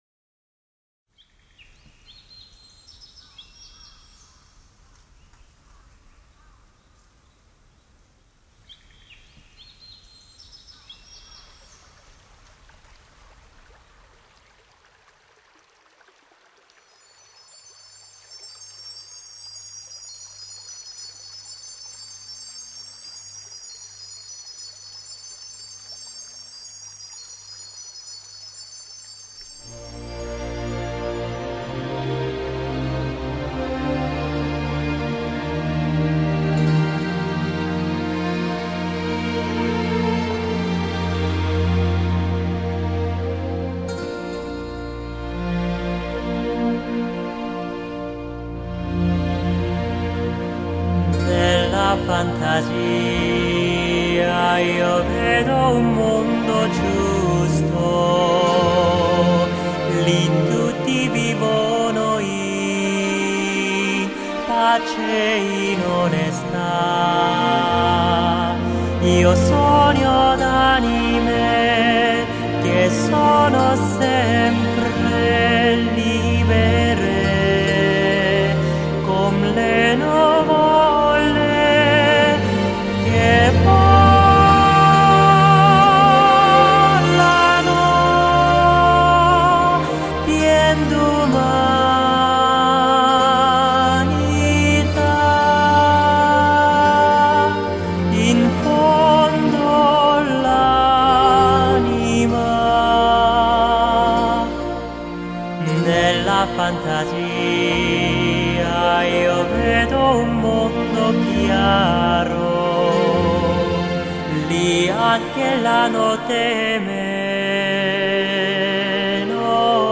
清澄宁静的嗓音隔绝了世俗的喧闹吵杂